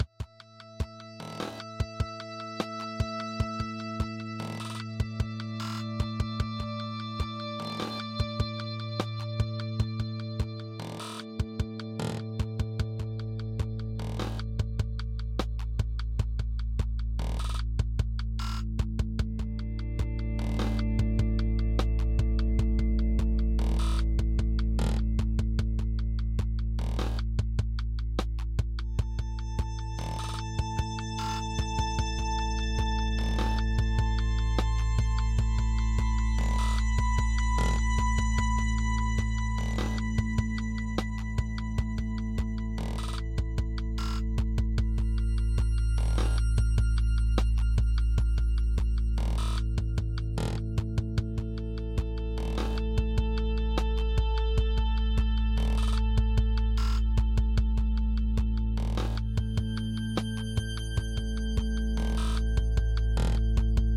Good tracked glitch music
Glitch can be quite nice with really short drums.
Next, learn how to use the retrigger and use Q81 to add glitchy sound effects.